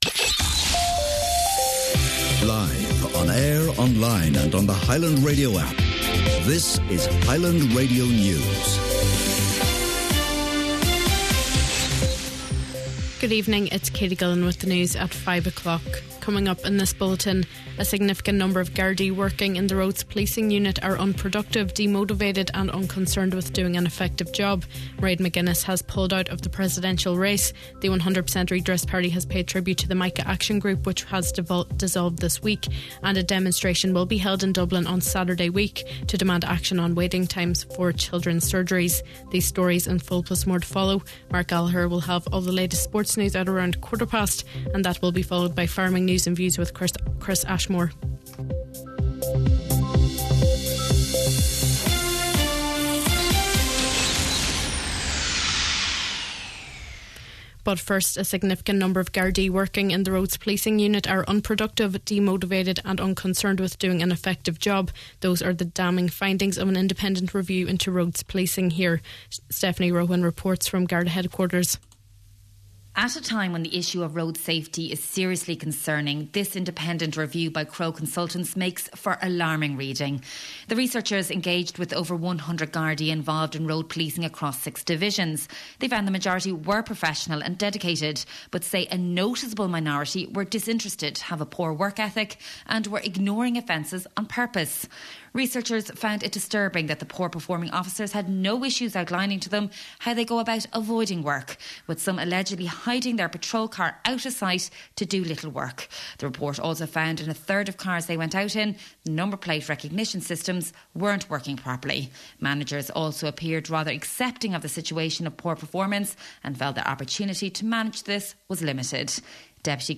Main Evening News, Sport, Farming News and Obituary Notices – Thursday August 14th